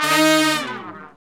Index of /90_sSampleCDs/Roland LCDP06 Brass Sections/BRS_Section FX/BRS_R&R Falls